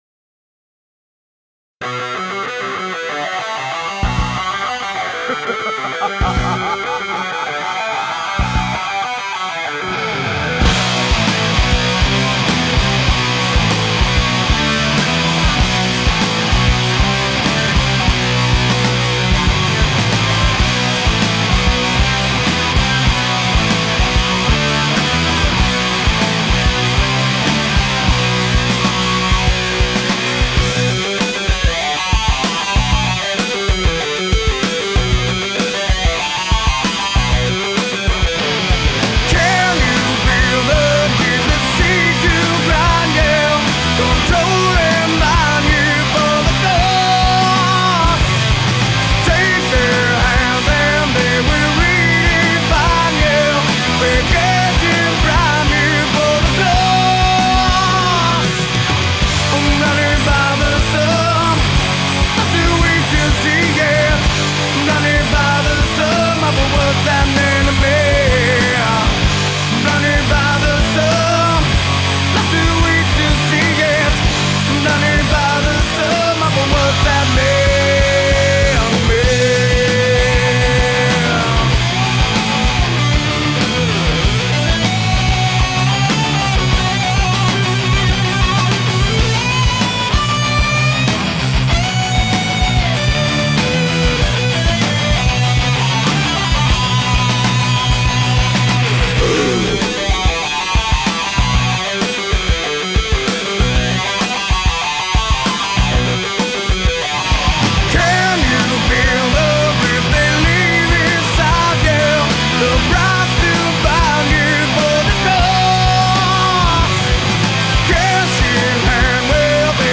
But man, what a voice.
A serious vocal talent.